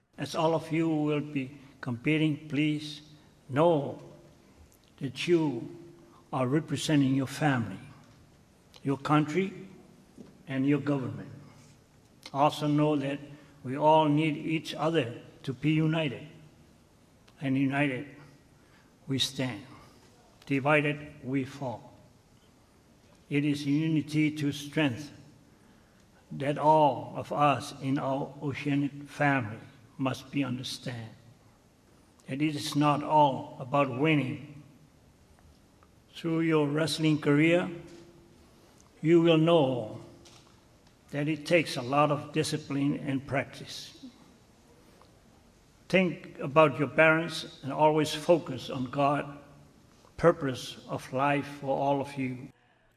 Pulu received applause when he said with the Governor being off island he is now the Governor and can take care of anything that the visiting teams may want.